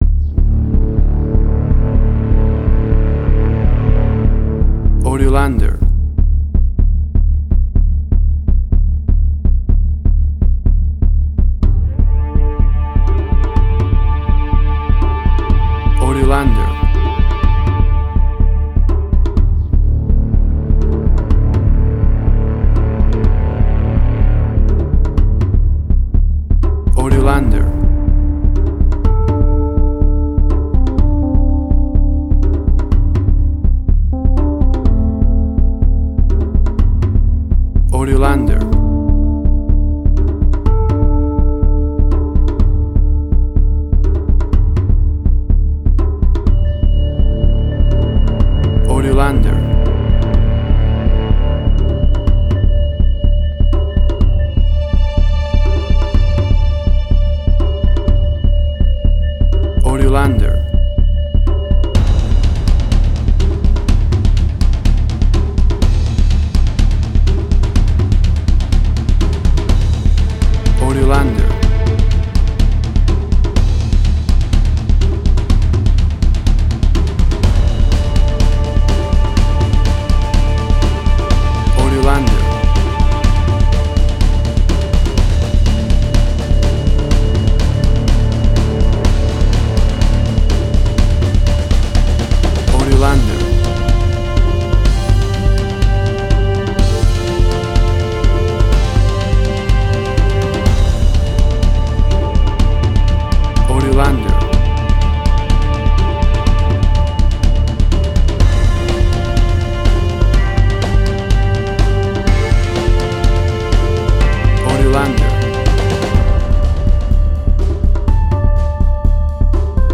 Suspense, Drama, Quirky, Emotional.
Tempo (BPM): 125